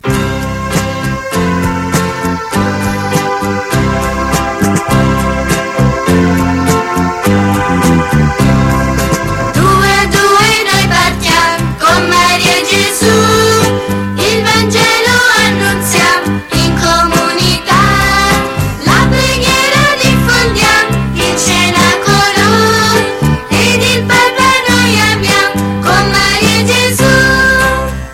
Canto per la Decina di Rosario e Parola di Dio: Due a due noi partiam